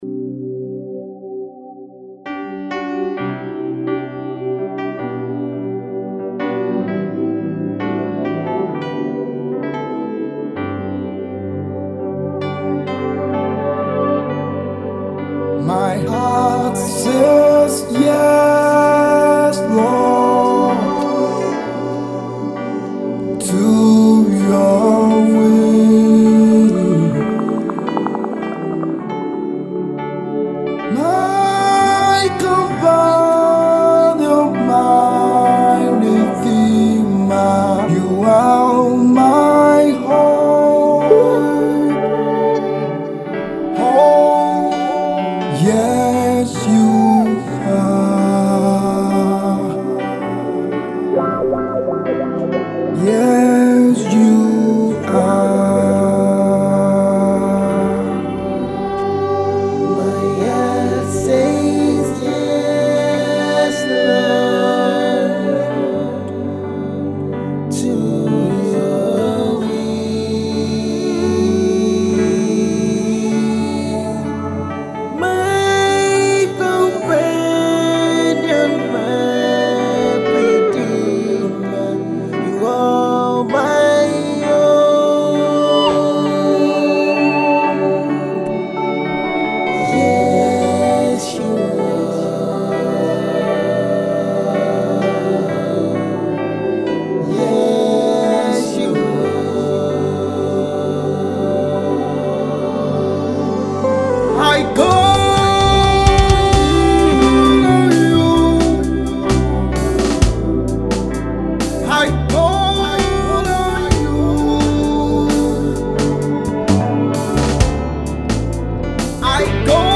Inspirational singer cum Gospel Trumpeter
soul lifting song